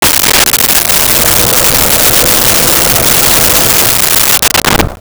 Bathroom Fan 01
Bathroom Fan 01.wav